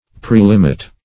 Prelimit \Pre*lim"it\, v. t. To limit previously.